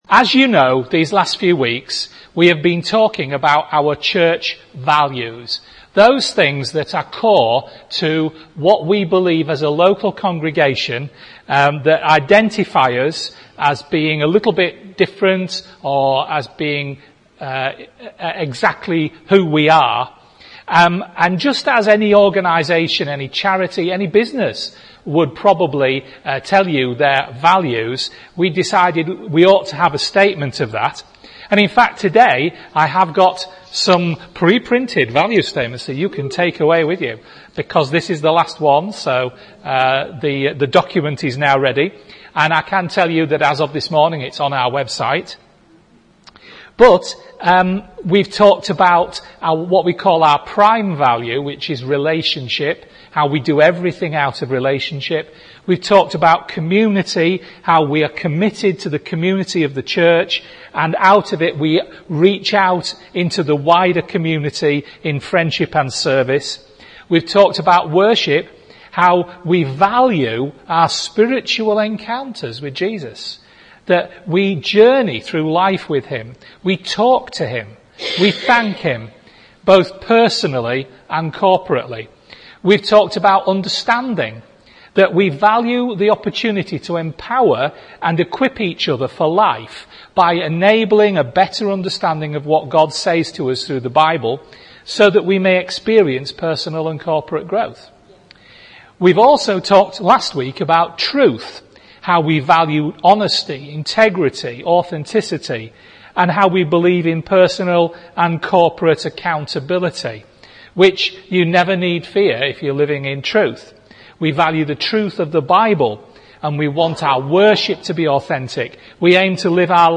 A preaching series that examines our core values as a church, ask ourselves why we hold them so precious, talk about their biblical basis and also what application we should see in our lives and in this church as we implement them. Today we discuss 'Doing Good'.